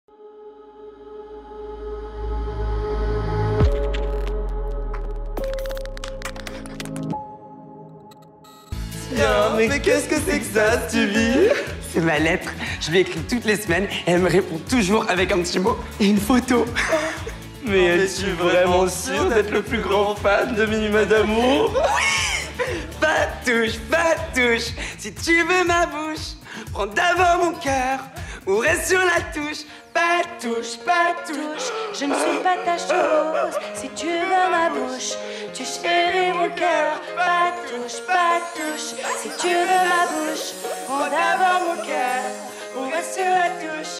Les-reines-du-drame-Trailer-Film-Fest-Gent-2024.mp3